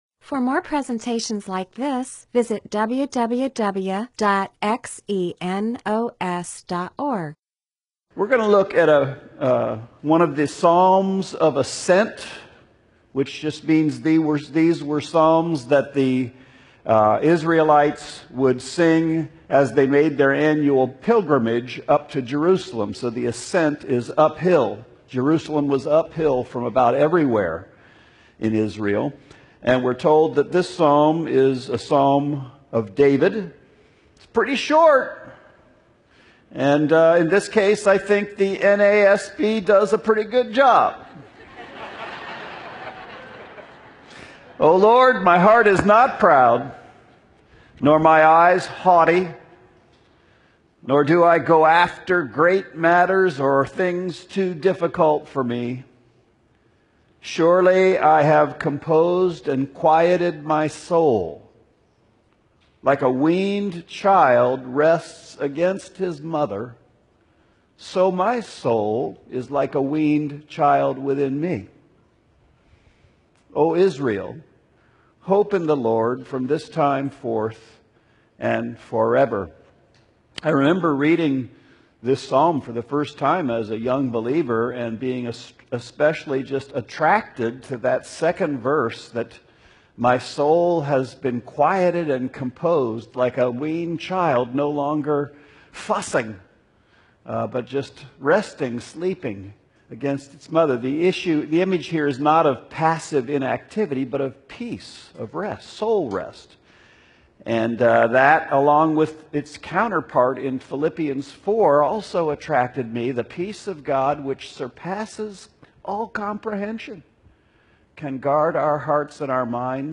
MP4/M4A audio recording of a Bible teaching/sermon/presentation about Psalms 131.